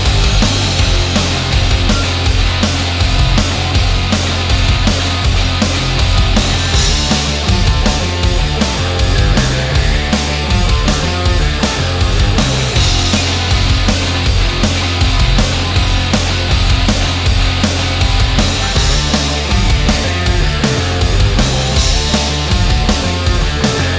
no Backing Vocals Indie / Alternative 2:53 Buy £1.50